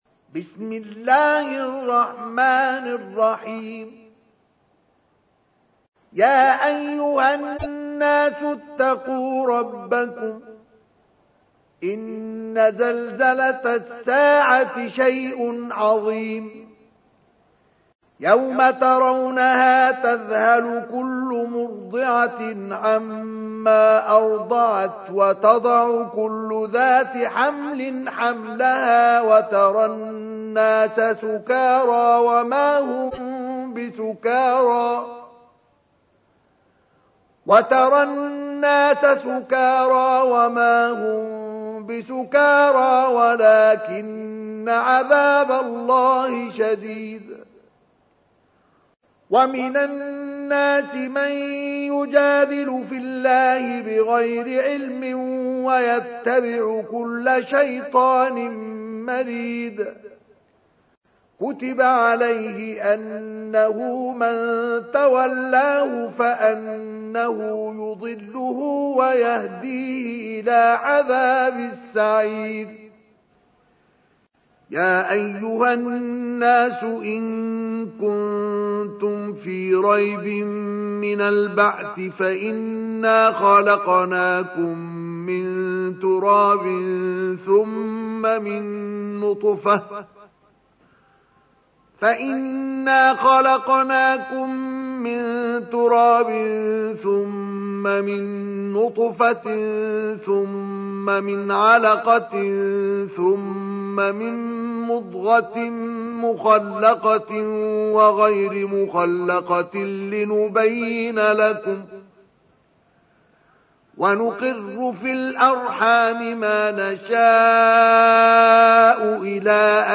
تحميل : 22. سورة الحج / القارئ مصطفى اسماعيل / القرآن الكريم / موقع يا حسين